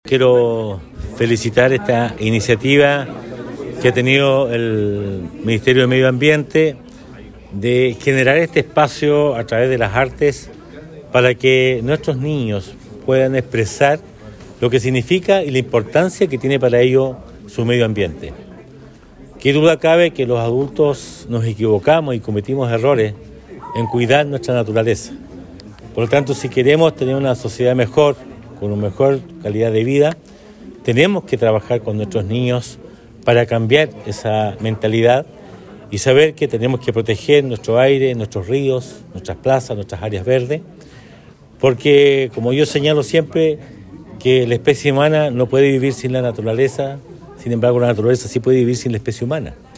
Por su parte el Alcalde Emeterio Carrillo, felicitó a los niños y niñas ganadores de este hermoso concurso, no sólo por su talento, sino también por tener la conciencia de cuidado de nuestro medio ambiente.